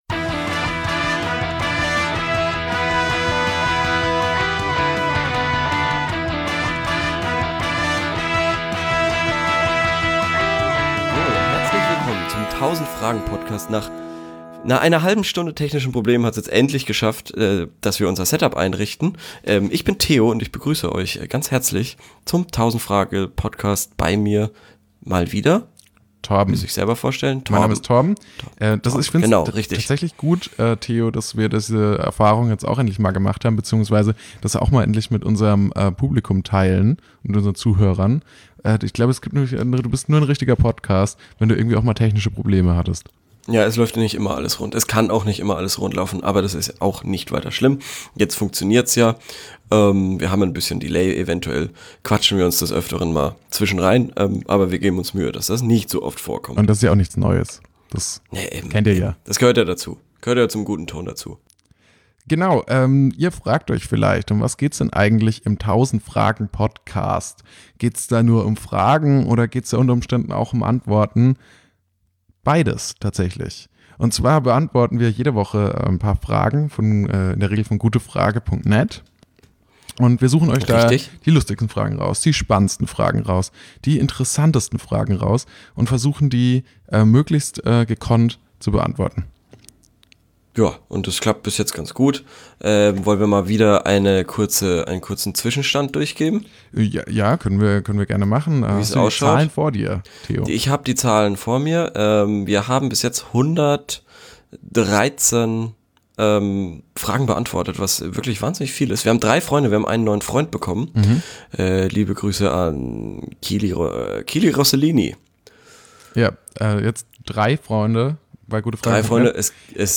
Diese Folge jedenfalls wieder im gewohnten Setting, jeder von zuhause aus. Unter anderem geben wir Pianisten Kampfnamen, erklären, was wir gerne einmal machen würden und diskutieren Freundschaft.